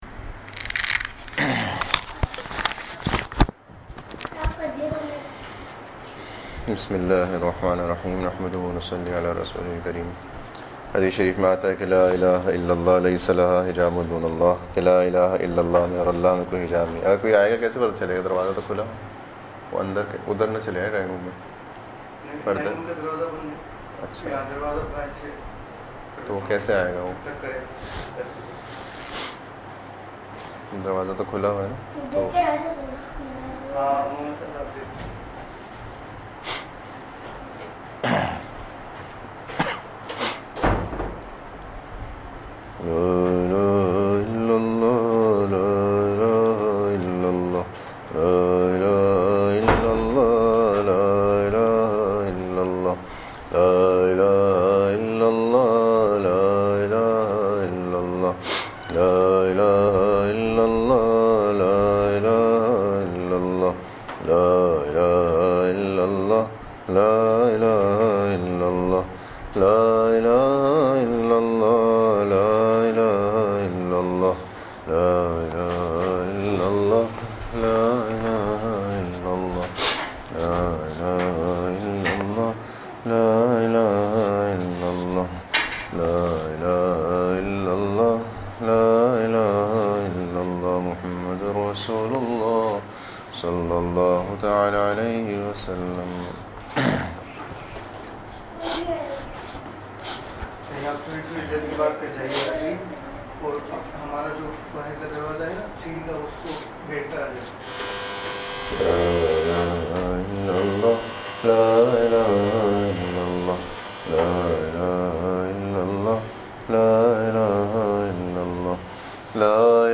15.3.26 (Special Majlis) Zikr Ashar Ghum Ka Mara Bhi Dy ,27 we Shabe Qader Ki Qader Karna ,Fazail ,Chaand Raat Eid Kay Din Nafs Kay Hamloo Gunaho Sy Bachnay Ka Zbardst Nuskha